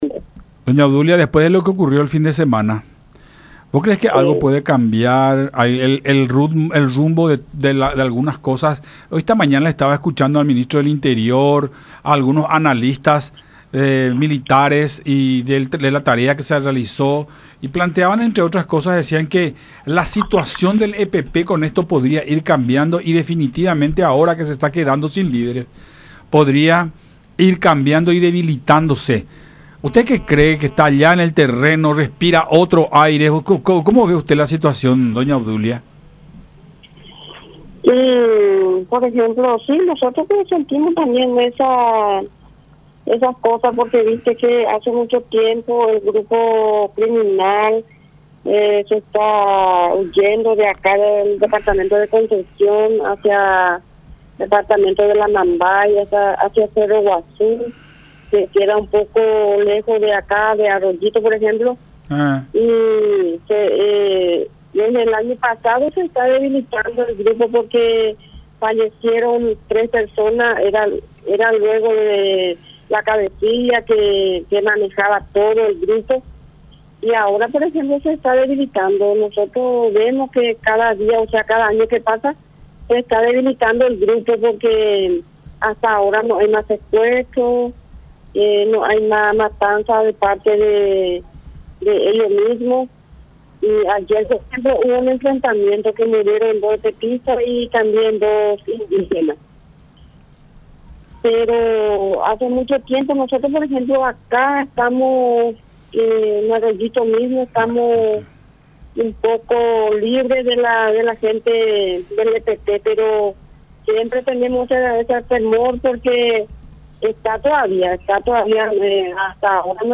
en charla con Buenas Tardes La Unión por Unión TV y radio La Unión